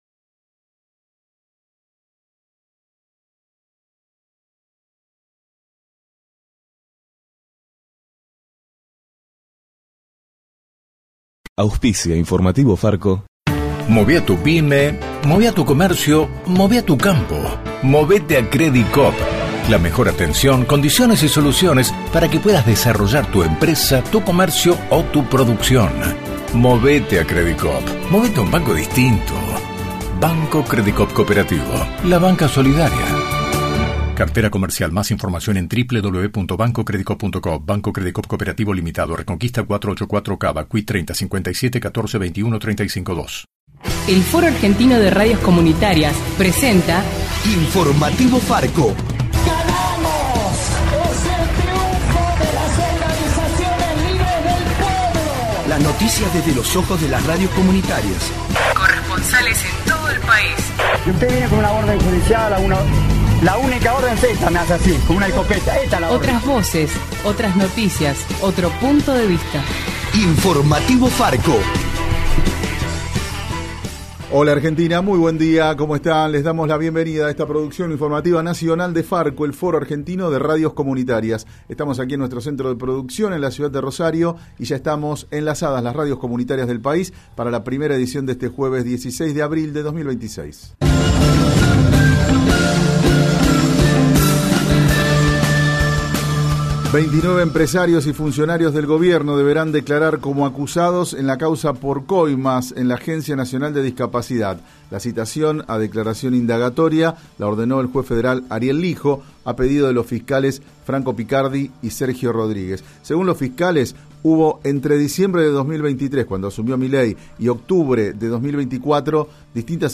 De la mano de FARCO (Foro Argentino de Radios Comunitarias) en Radio Atilra te acercamos el informativo más federal del país.